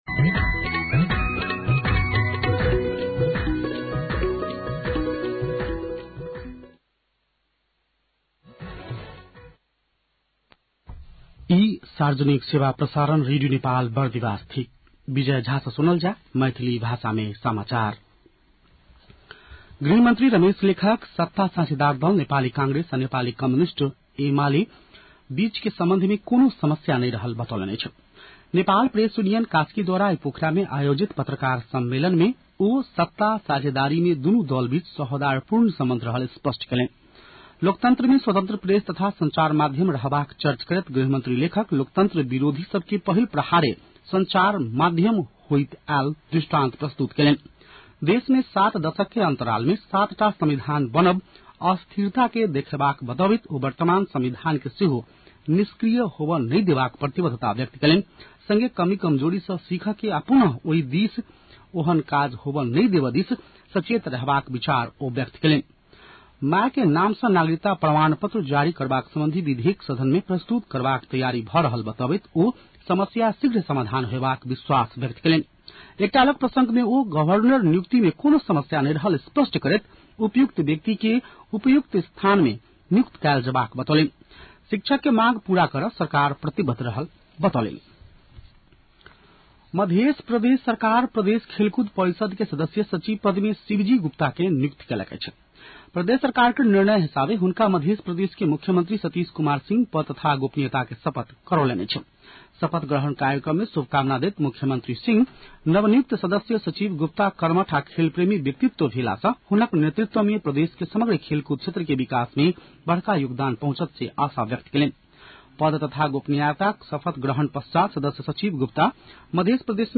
मैथिली भाषामा समाचार : ६ वैशाख , २०८२